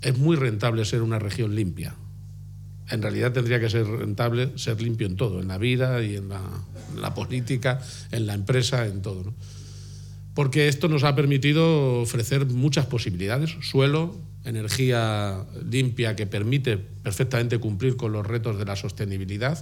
>> García-Page anuncia la medida durante la inauguración del centro de datos de Fortinet en Torija, Guadalajara